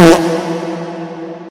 normal-hitwhistle.mp3